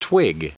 Prononciation du mot twig en anglais (fichier audio)
Prononciation du mot : twig